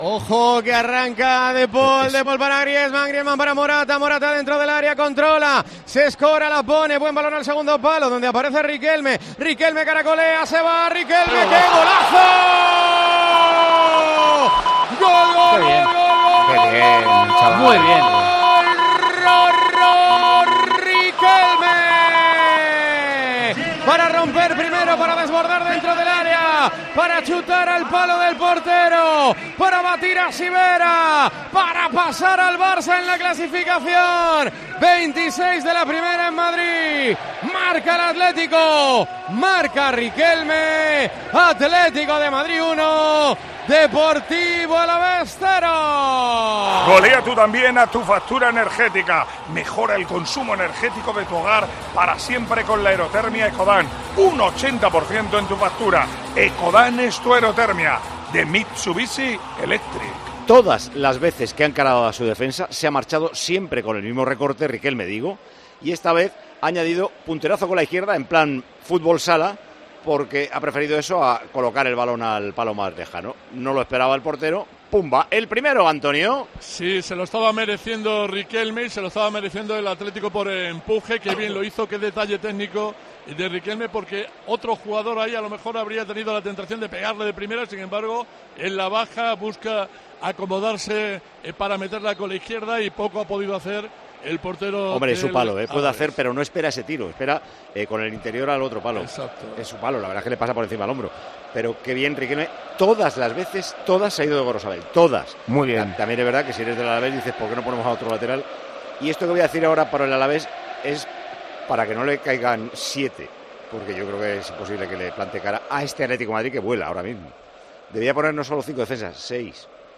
El comentarista de Tiempo de Juego señaló el buen momento del Atlético de Madrid, que encadena e iguala su récord de 14 victorias consecutivas en Primera en el Metropolitano.